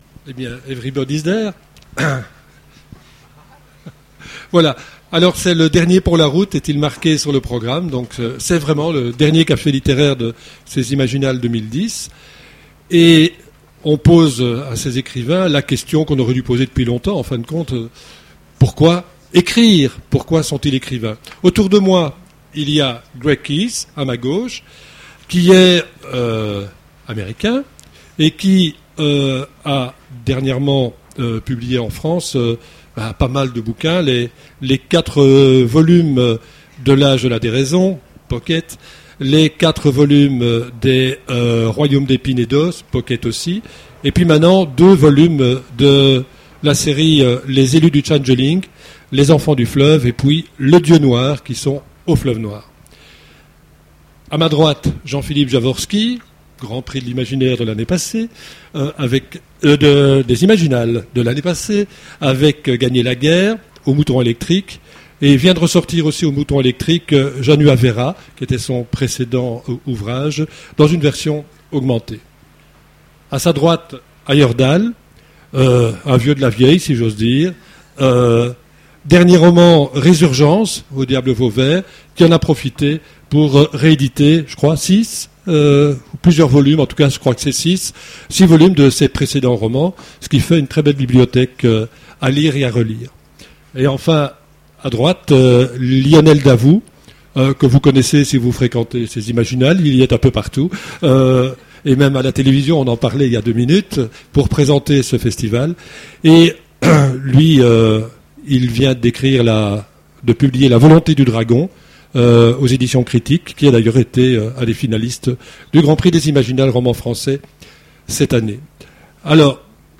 Voici l'enregistrement de la conférence Pourquoi être écrivain ? aux Imaginales 2010